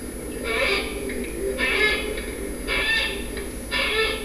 Parabuteo unicinctus unicinctus - Gavilán mixto